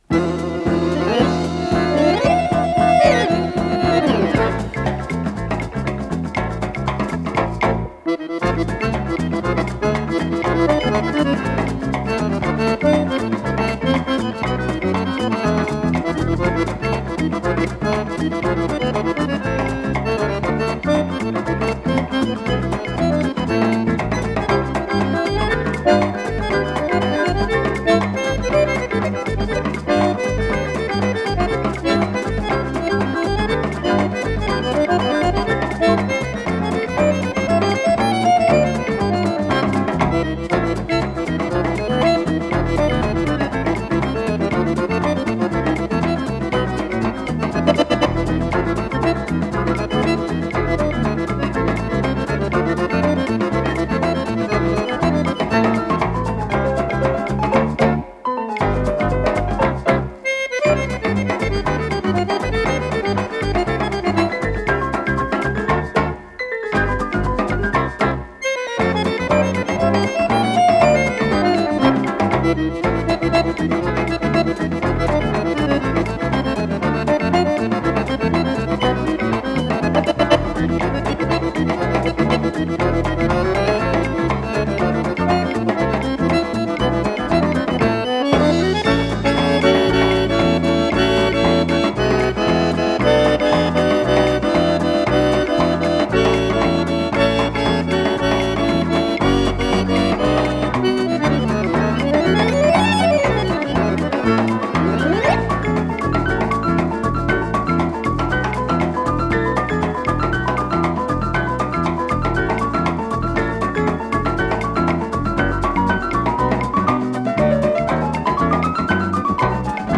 ПодзаголовокСамба
АккомпаниментИнструментальный ансамбль
ВариантДубль моно